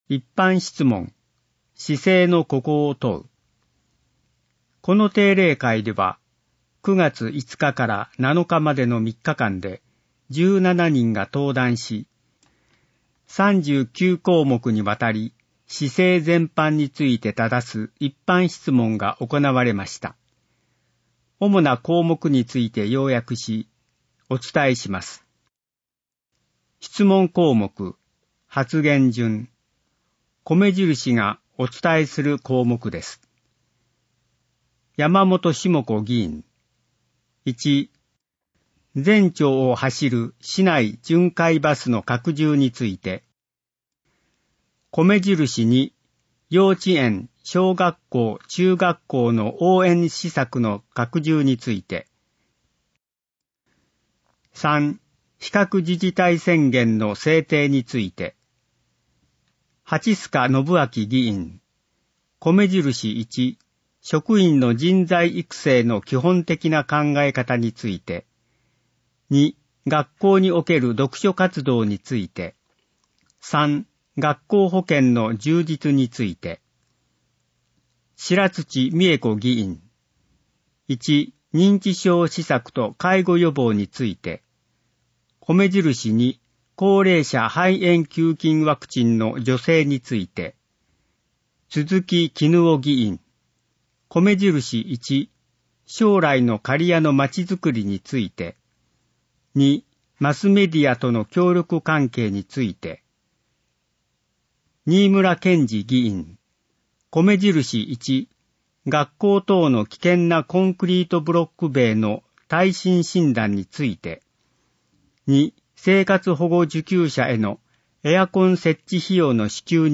声の議会だより 平成30年11月1日号